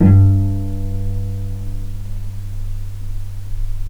vc_pz-G2-pp.AIF